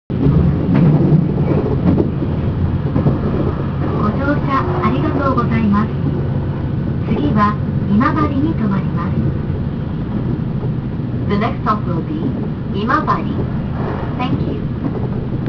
・8000系 車内自動放送（更新）
上記の音声では流れていないものの、車内チャイムに変更はないようです。